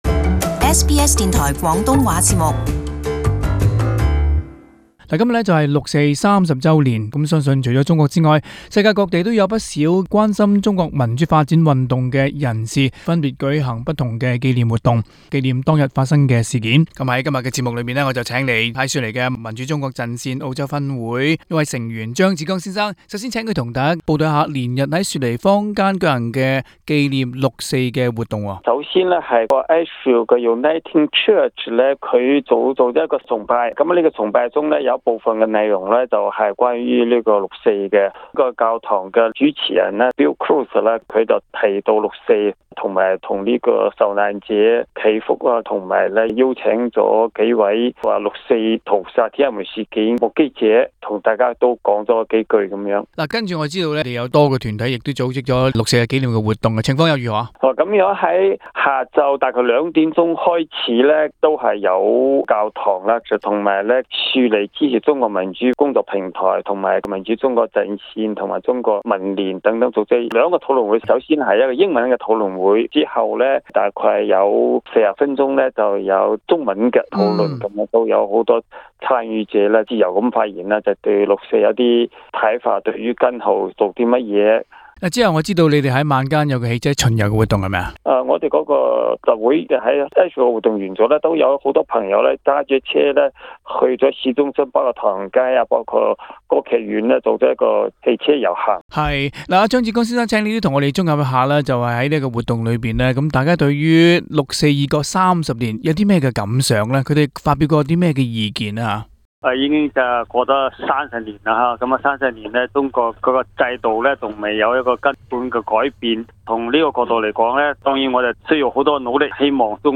【社區専訪】毋忘六四30周年紀念